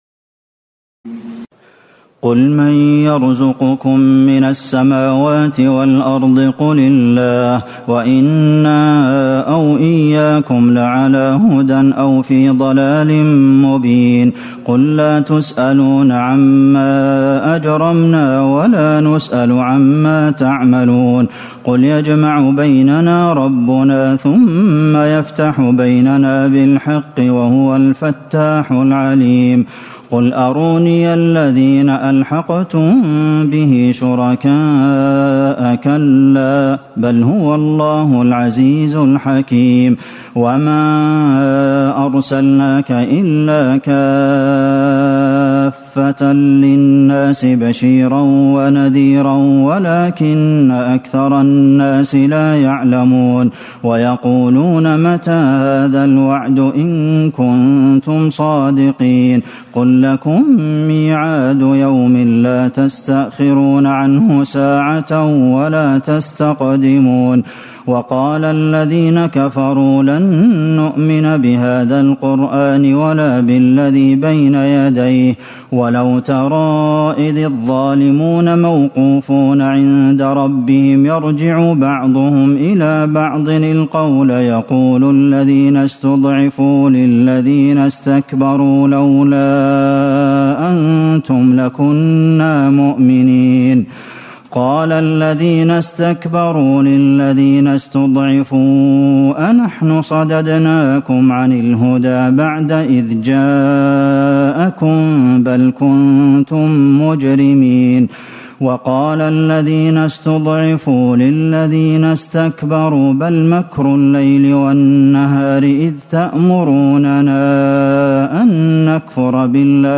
تراويح ليلة 21 رمضان 1437هـ من سور سبأ (24-54) وفاطر و يس(1-32) Taraweeh 21 st night Ramadan 1437H from Surah Saba and Faatir and Yaseen > تراويح الحرم النبوي عام 1437 🕌 > التراويح - تلاوات الحرمين